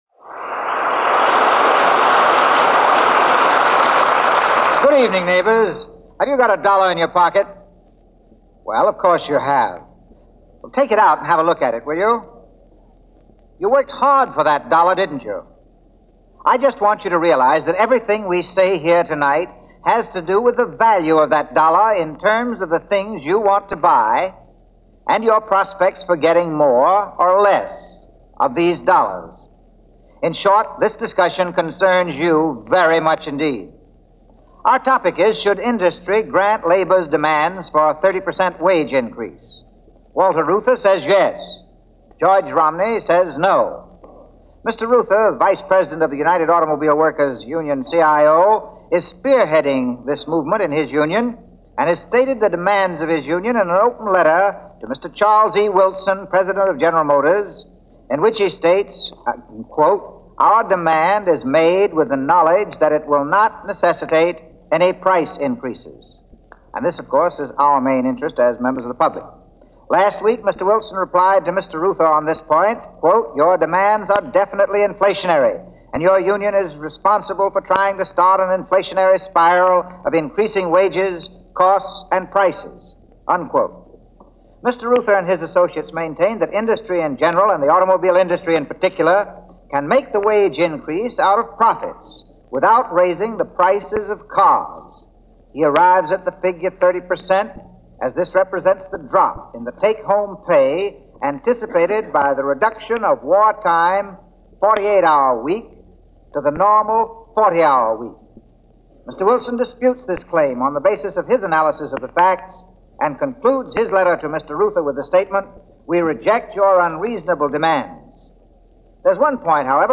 Romney and Reuther discuss labor in 1945 - George Romney of the Auto Industry and Walter Reuther of the Autor Workers Union discuss wage increases.